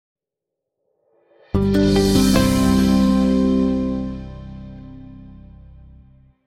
Звуки выигрыша
Звук победной мелодии